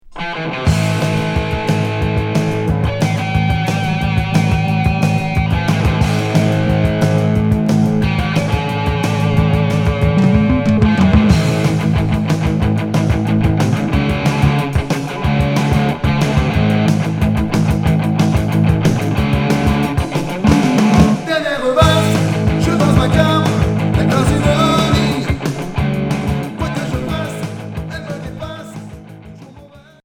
Rock punk hard